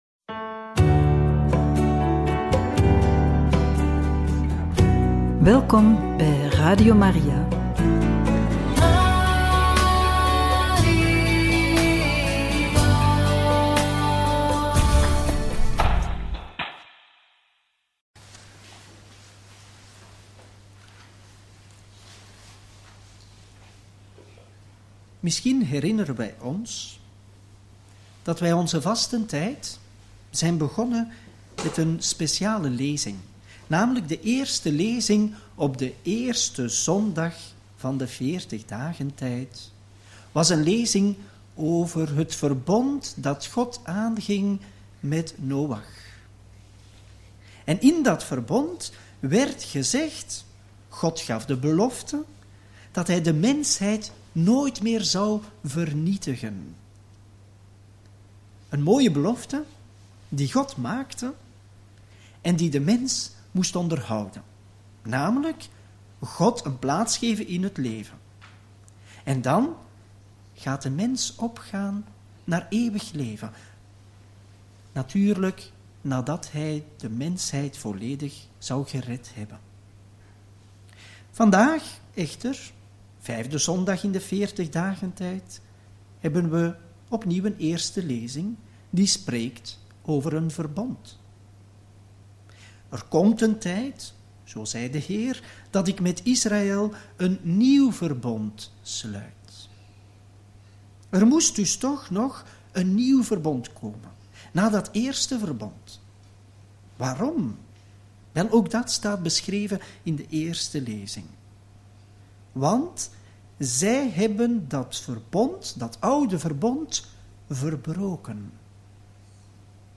Homilie op de vijfde zondag van de vastentijd – Radio Maria
homilie-op-de-vijfde-zondag-van-de-vastentijd.mp3